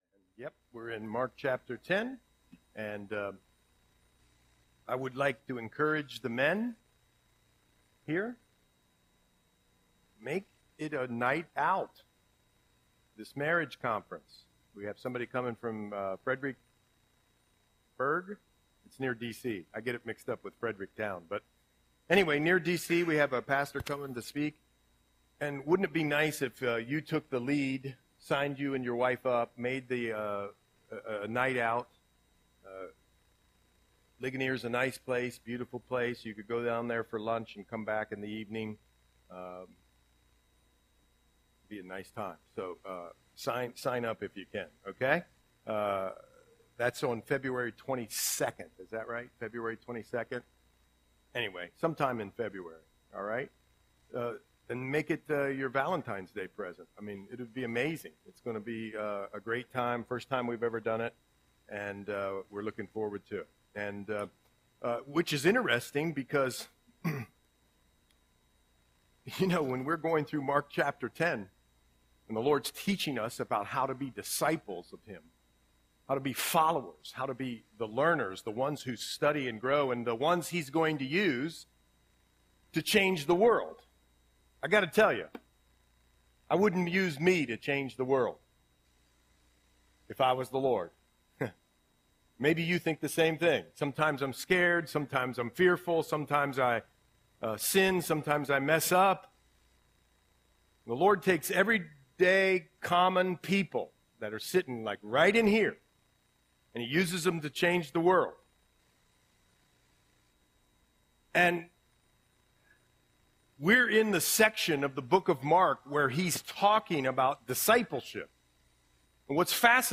Audio Sermon - February 2, 2025